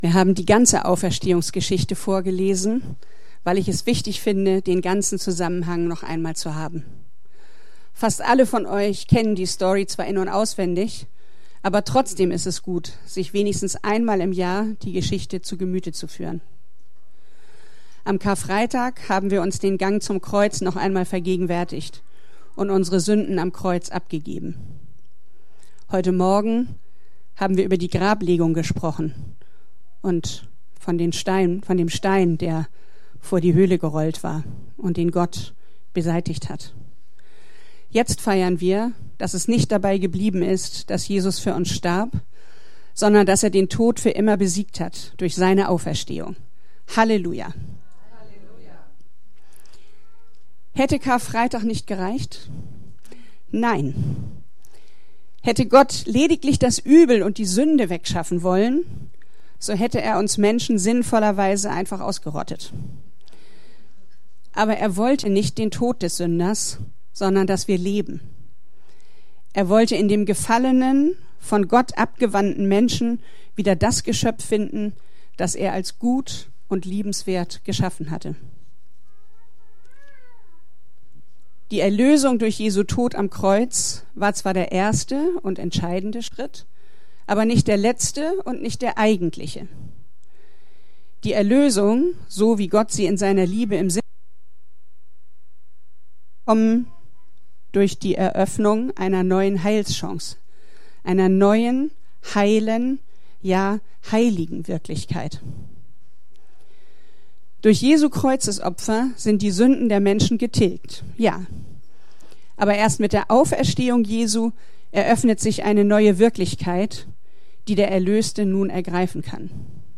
Ostergottesdienst